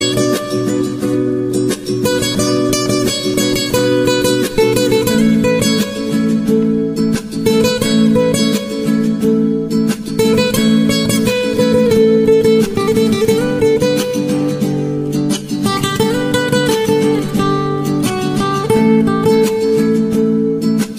آهنگ زنگ گیتار رمانتیک و احساسی